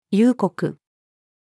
憂国-female.mp3